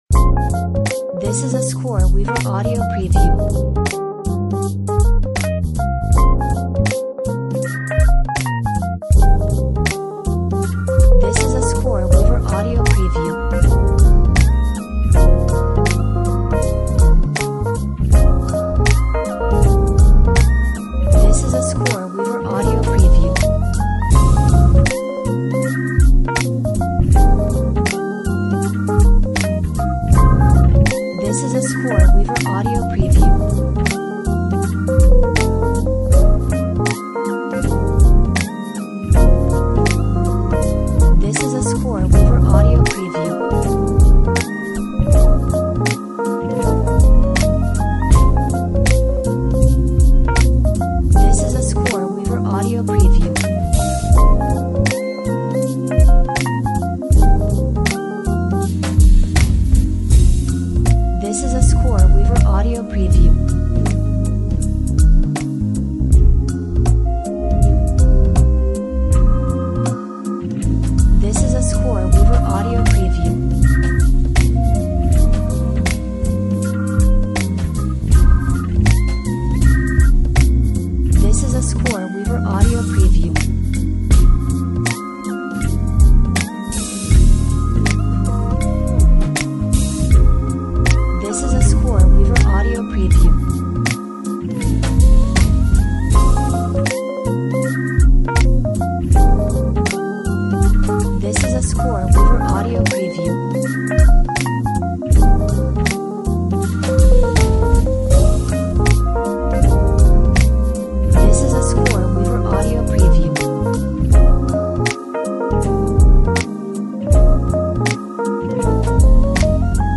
Smooth Soul/RnB Song